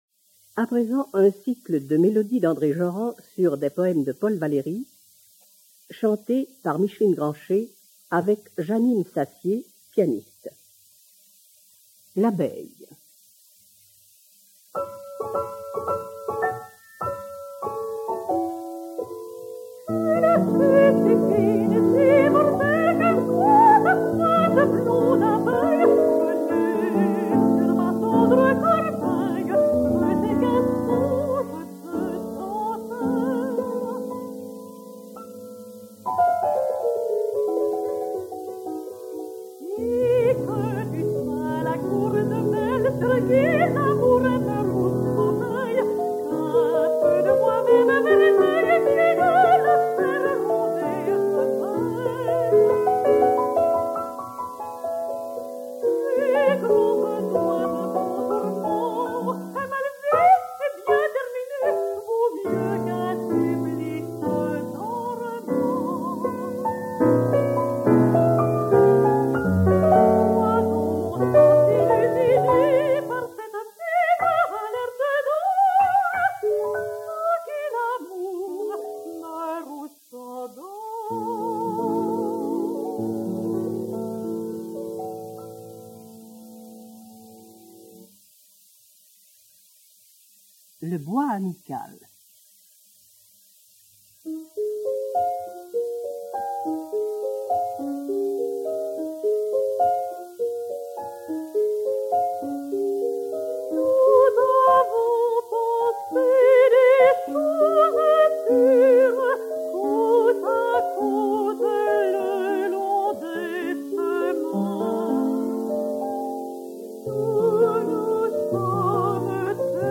mezzo-soprano français
mélodies
piano